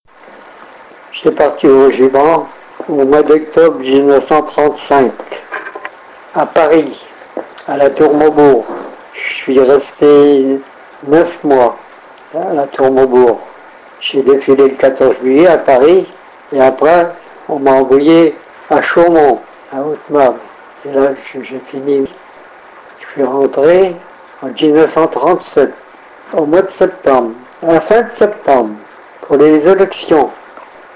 Extrait de l'interview d'un soldat bellopratain fait prisonnier en mai 1940 (avec l'accord des familles)